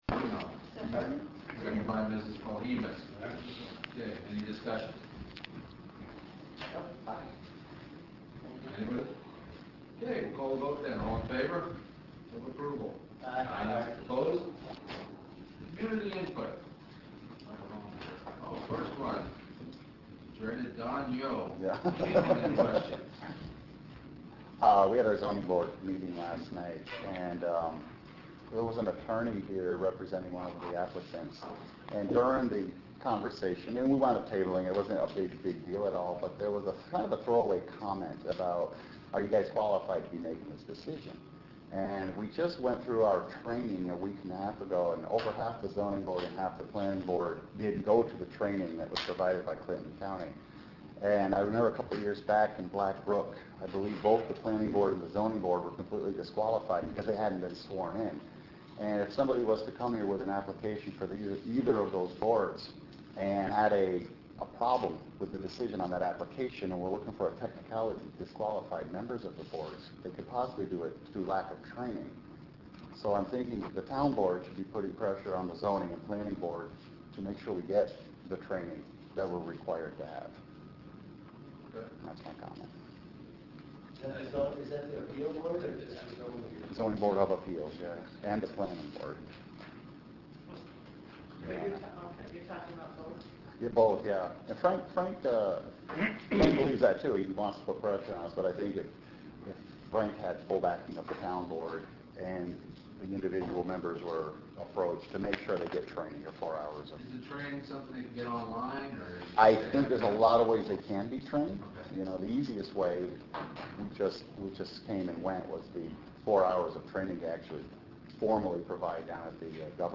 Peru’s wave of home and business break-ins dominated much of the discussion at the December 22, 2011 Town Board meeting.